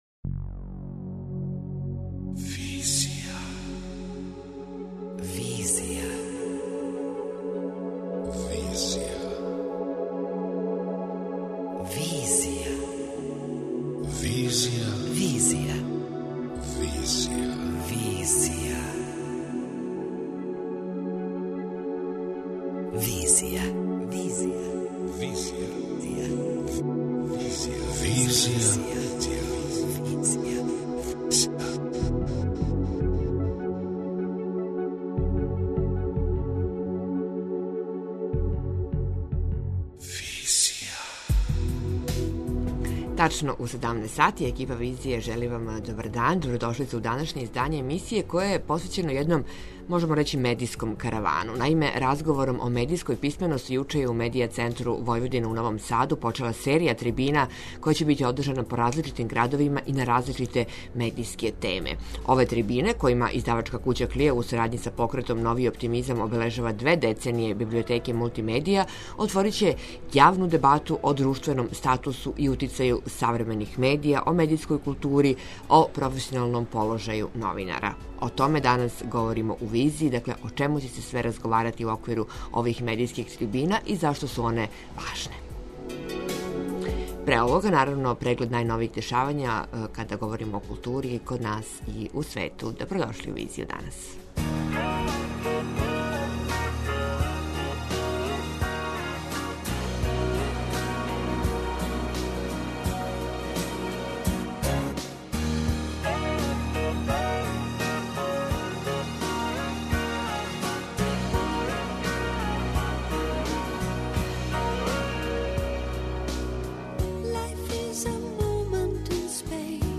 преузми : 27.28 MB Визија Autor: Београд 202 Социо-културолошки магазин, који прати савремене друштвене феномене.